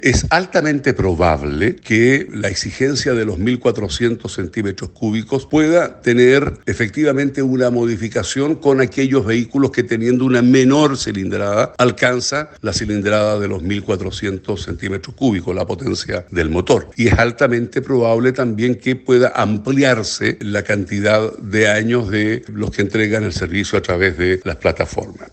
Por su parte, el diputado de la bancada del PPD, Carlos Bianchi, que también forma parte de la comisión, adelantó algunas de las modificaciones que podrían concretarse en el reglamento.